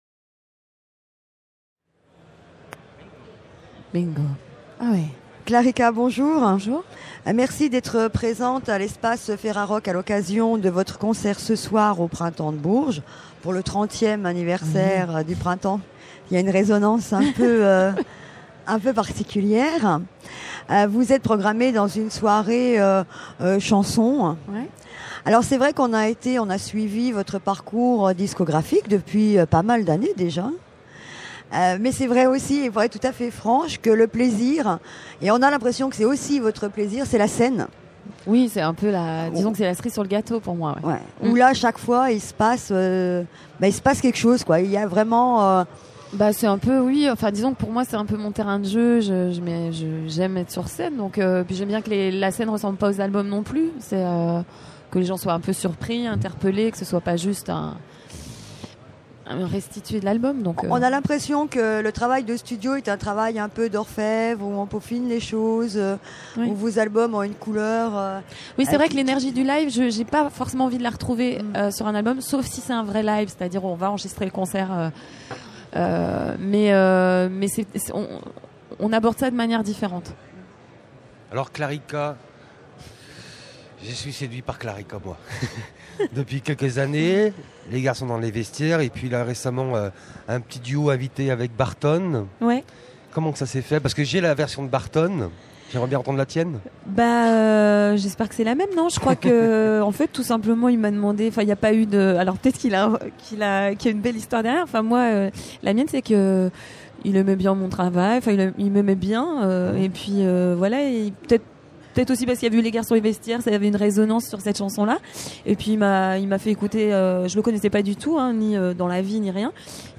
Clarika Festival du Printemps de Bourges 2006 : 40 Interviews à écouter !